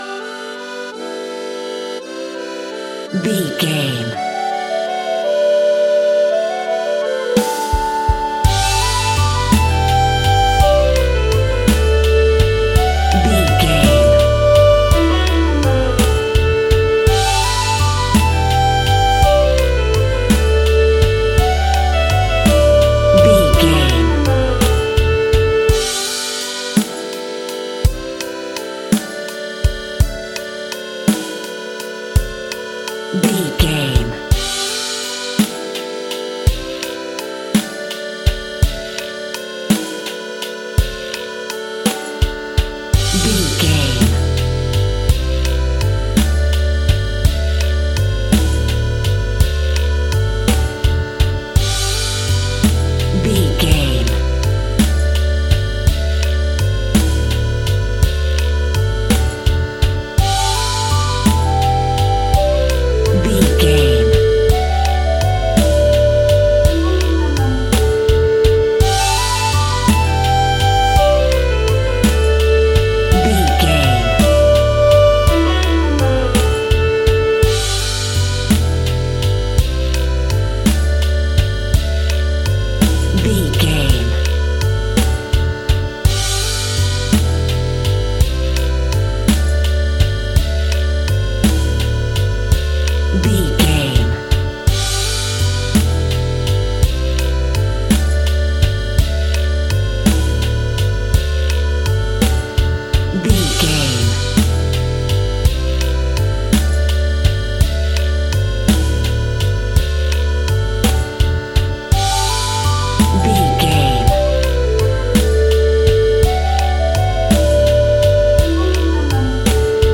Uplifting
Ionian/Major
country
blues
folk
drums
bass guitar
electric guitar
piano
hammond organ
percussion